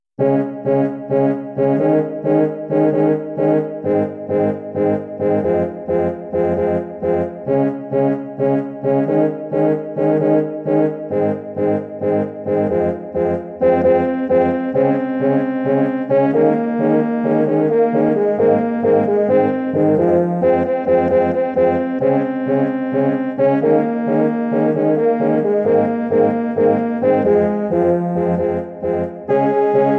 Arrangement / Hornensemble / Hornoktett / Rock/Pop
Bearbeitung für Hornoktett
Besetzung: 8 Hörner
Arrangement for horn octet
Instrumentation: 8 horns